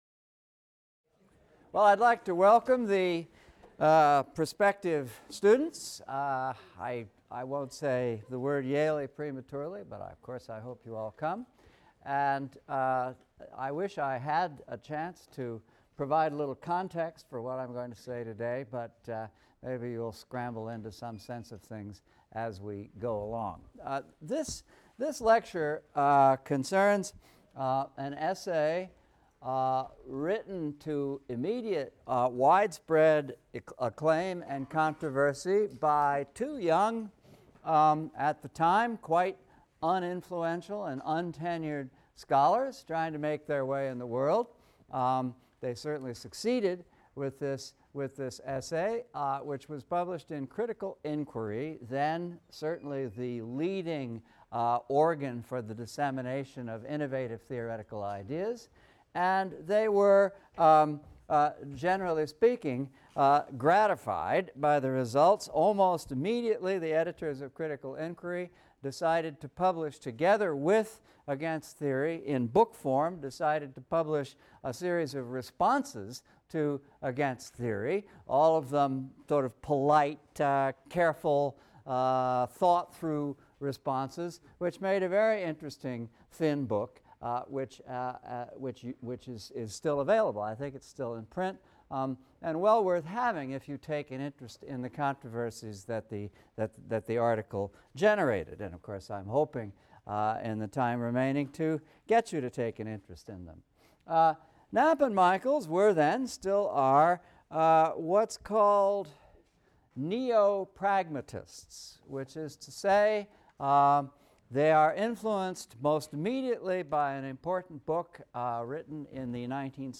ENGL 300 - Lecture 25 - The End of Theory?; Neo-Pragmatism | Open Yale Courses